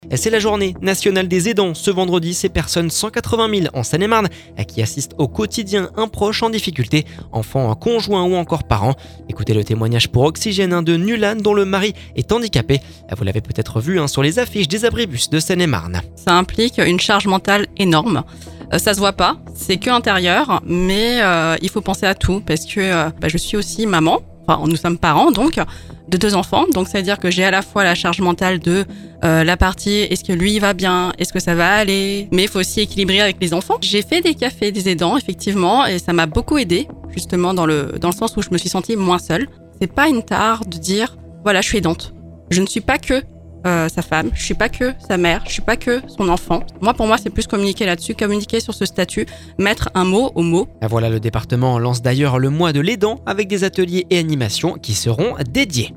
TEMOIGNAGE - La journée nationale des aidants ce vendredi